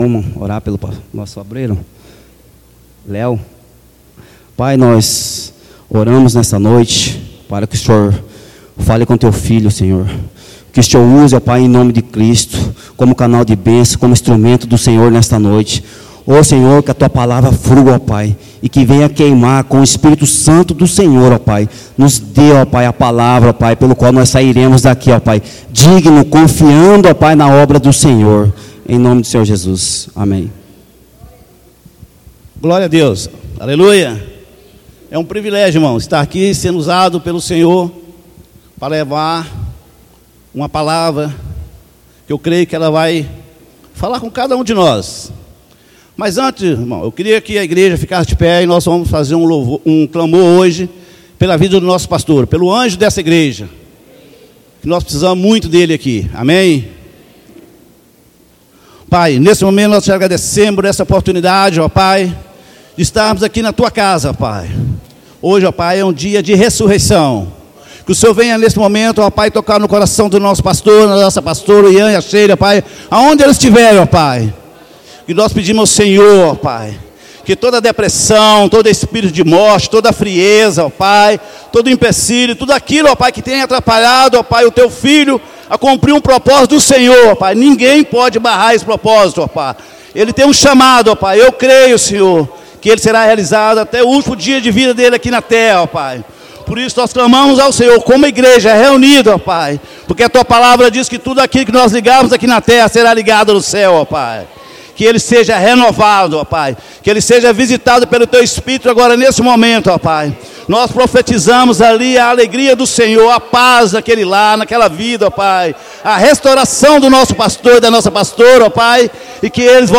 Em Culto de Celebração